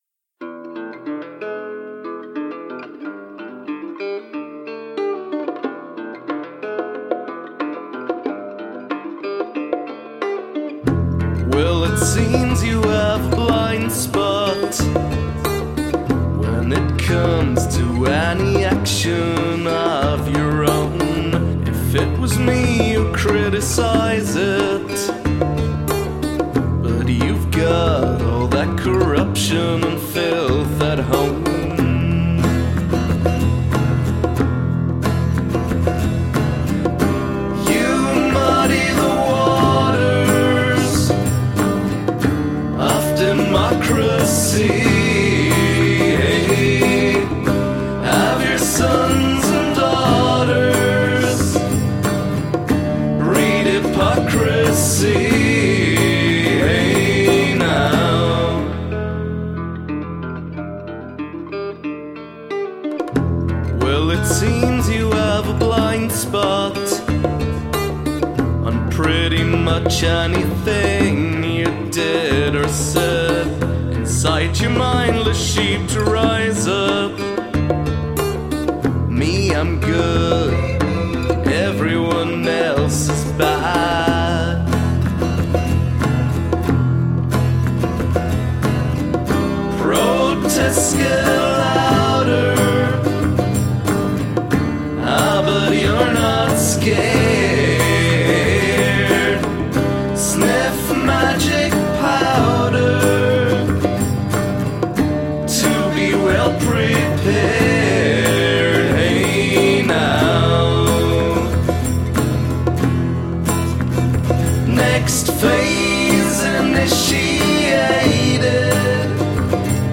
Hand percussion